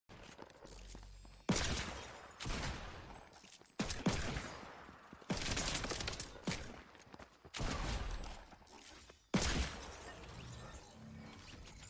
OG Fortnite - Little Bit Of Revolver, Shotgun Action